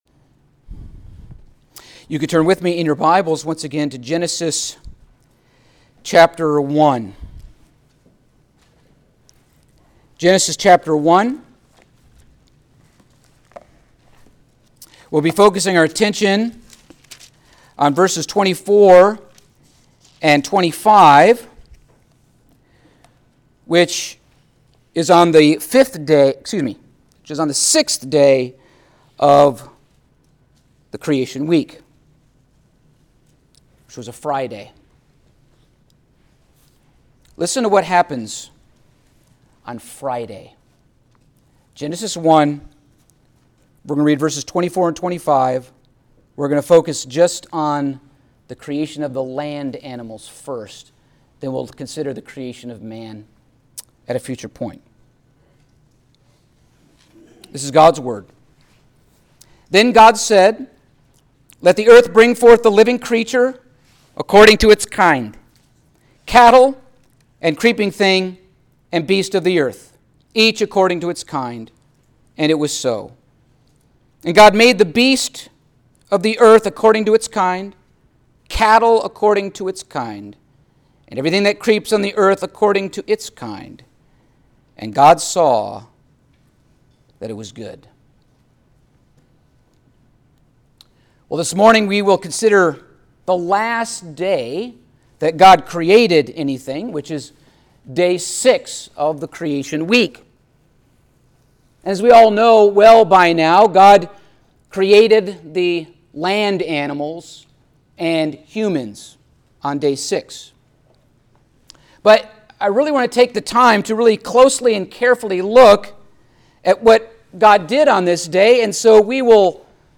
Passage: Genesis 1:24-25 Service Type: Sunday Morning